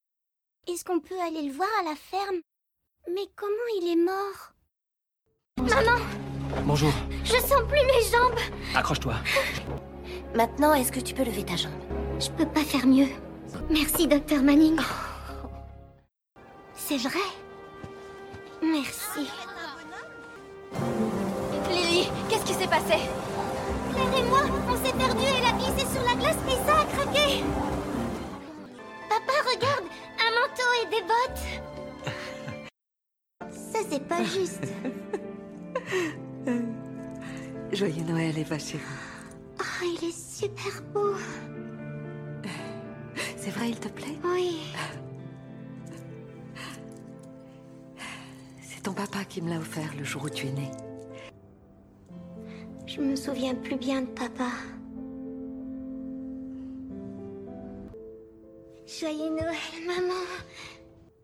Demo voix petite fille
J'ai une voix jeune, légère, cristalline et pétillante...